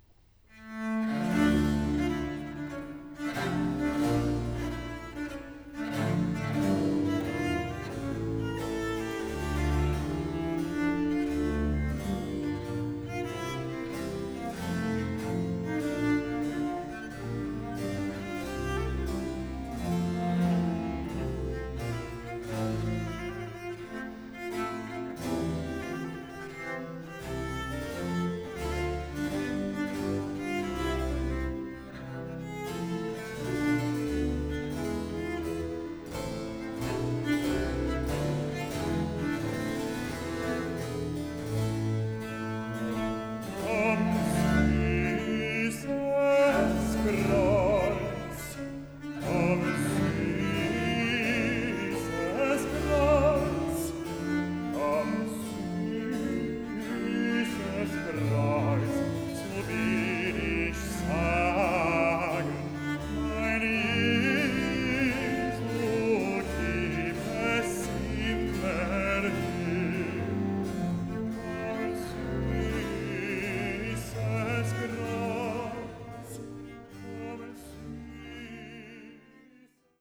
enregistrement haute définition en « live »